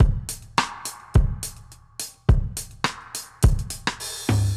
Index of /musicradar/dub-drums-samples/105bpm
Db_DrumsA_Wet_105_03.wav